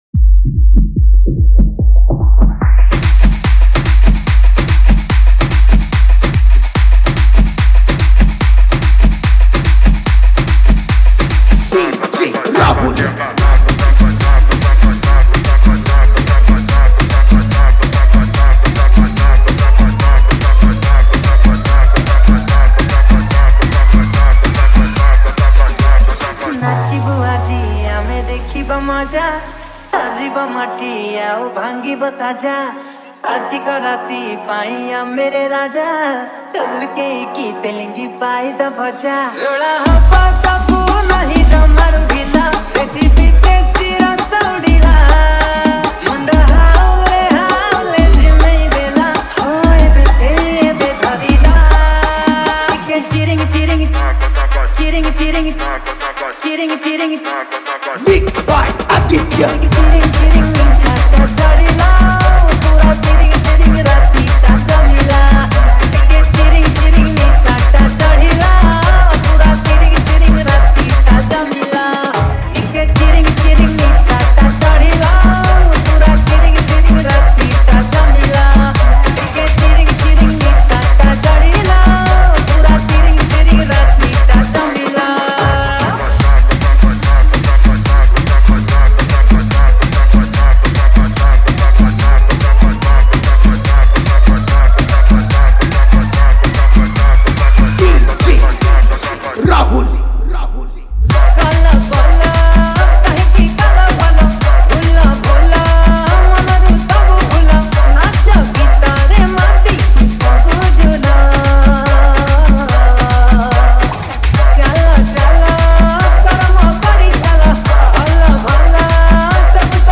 Single Dj Song Collection 2025 Songs Download